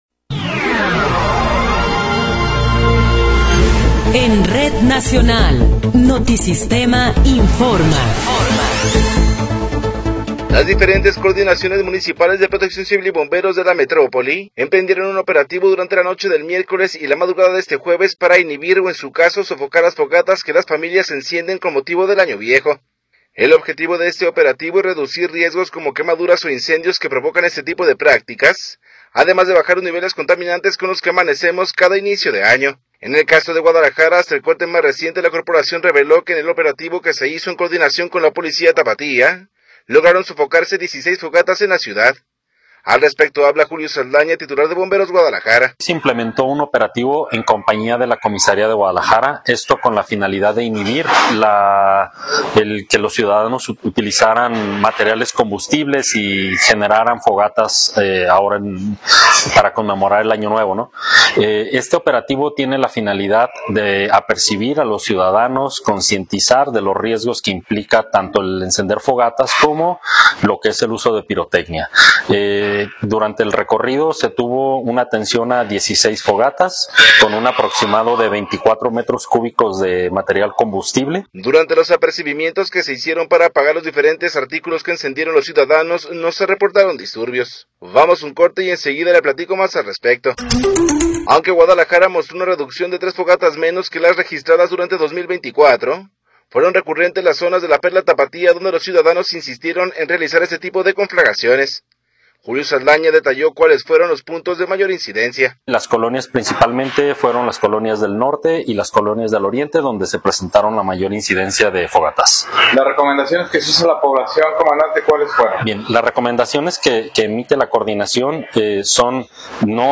Noticiero 11 hrs. – 1 de Enero de 2026
Resumen informativo Notisistema, la mejor y más completa información cada hora en la hora.